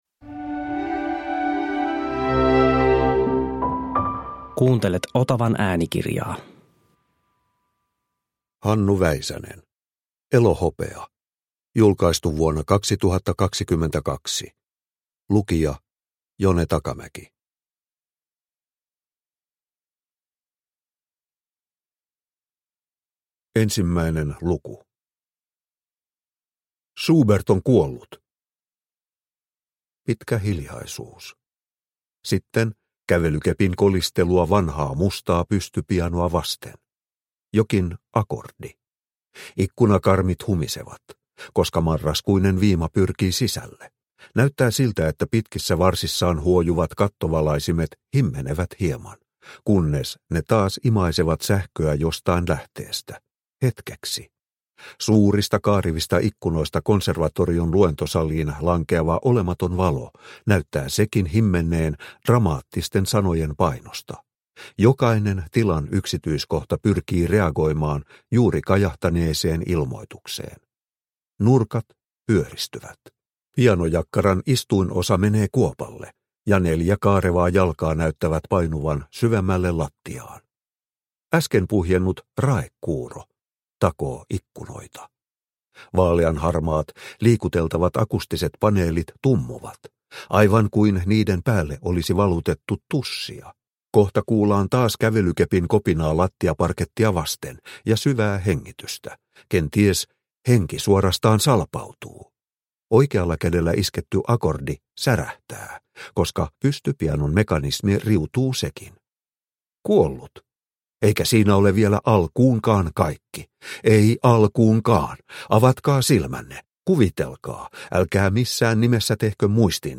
Elohopea – Ljudbok – Laddas ner